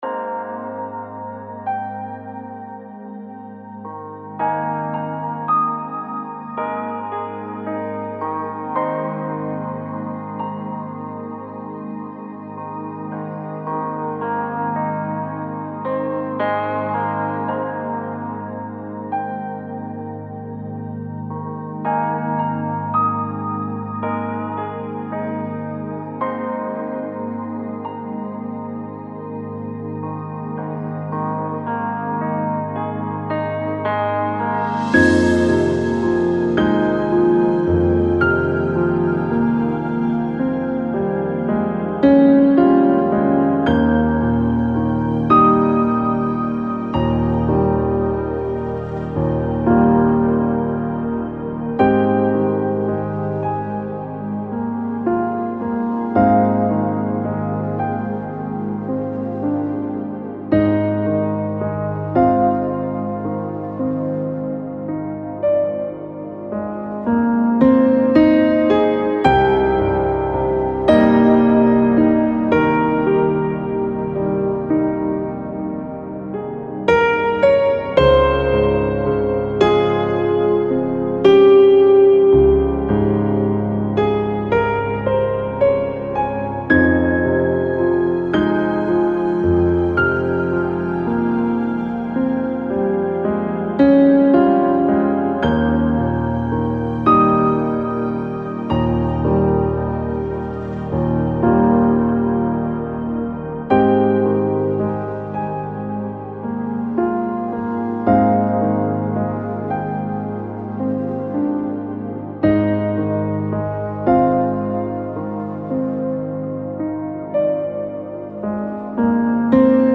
Жанр: Ambient, Chillout, PsyChill